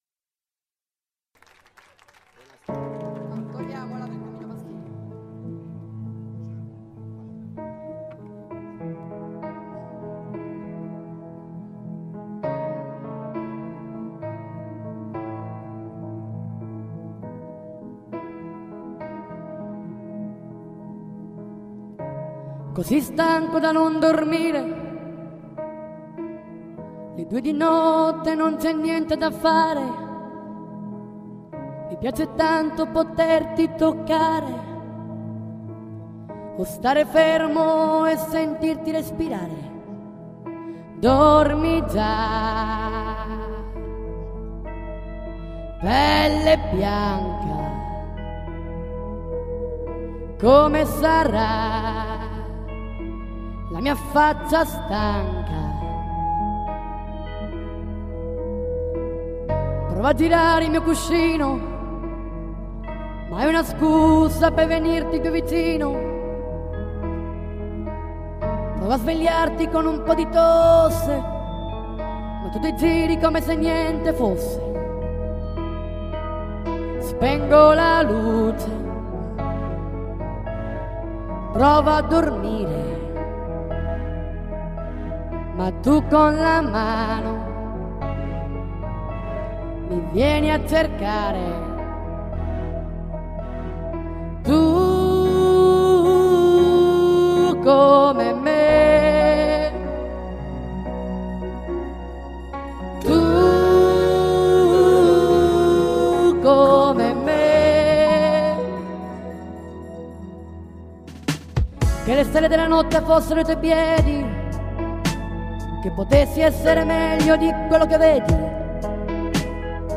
Kantabeach 10° Edizione 2013.